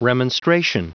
Prononciation du mot remonstration en anglais (fichier audio)
Prononciation du mot : remonstration